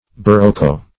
barocco - definition of barocco - synonyms, pronunciation, spelling from Free Dictionary Search Result for " barocco" : The Collaborative International Dictionary of English v.0.48: Barocco \Ba*roc"co\, a. [It.]